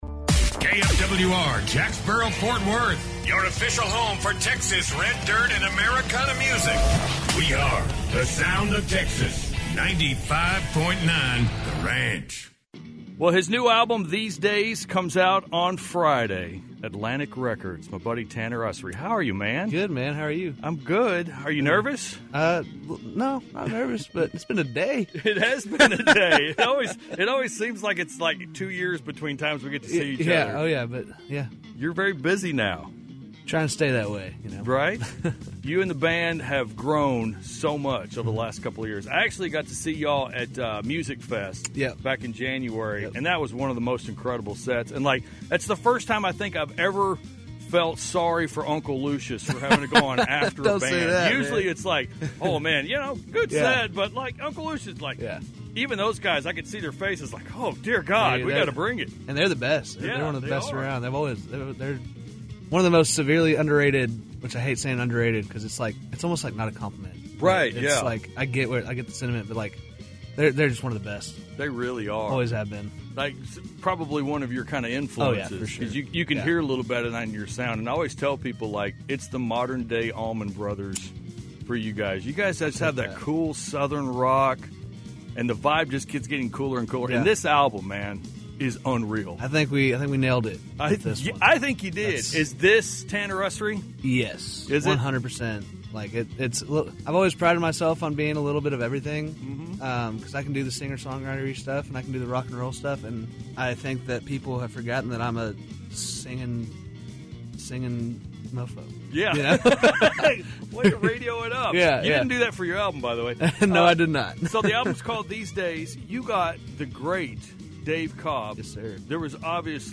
In a rare and revealing conversation